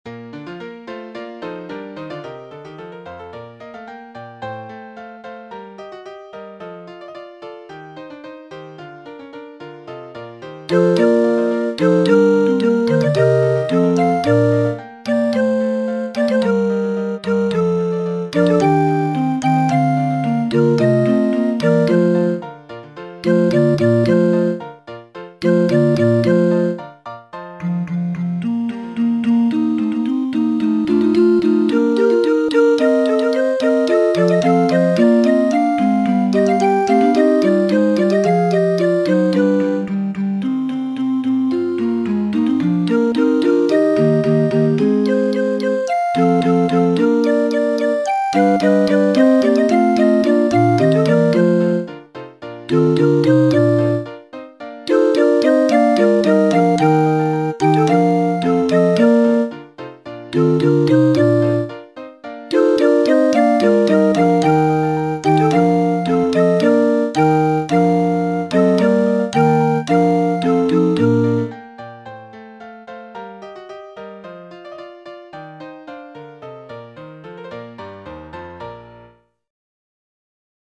25 -Chorus